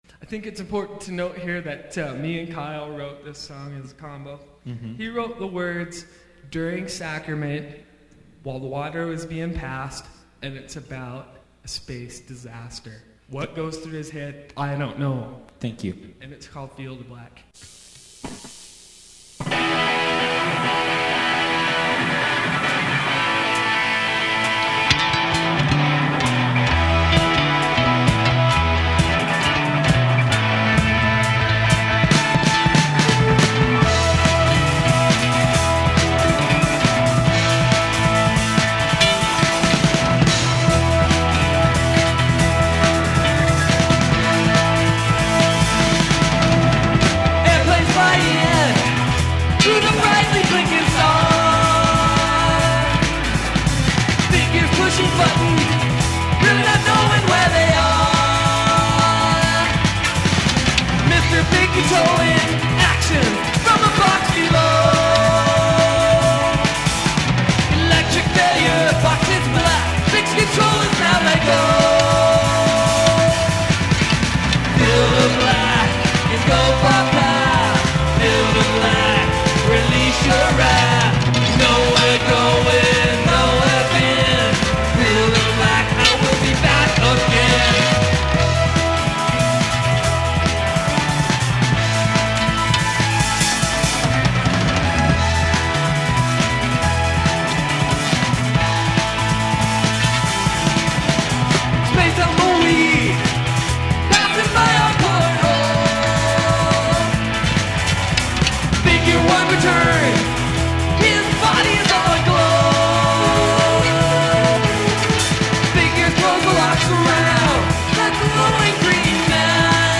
The instrumental intro is dropped